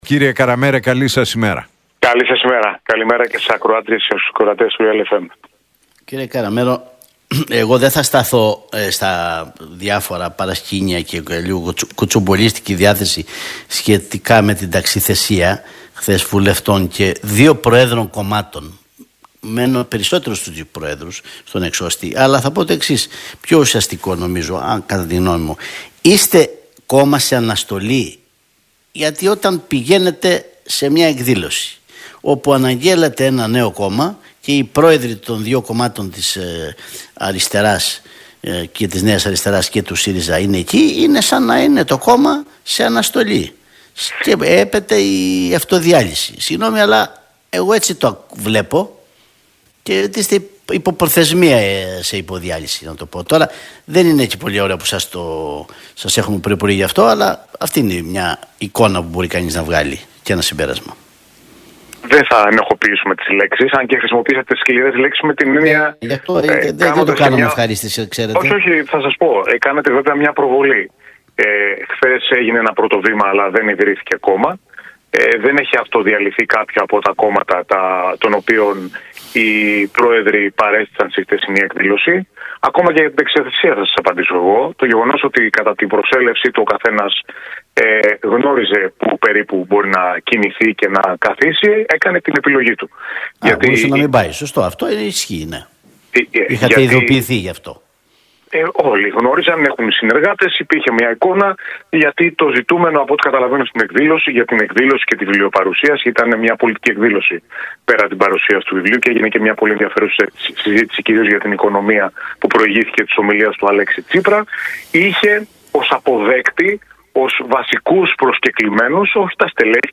Ο βουλευτής του ΣΥΡΙΖΑ, Γιώργος Καραμέρος, μίλησε στον Realfm 97,8 για την παρουσίαση του βιβλίου του Αλέξη Τσίπρα και τις διεργασίες στην Κεντροαριστερά.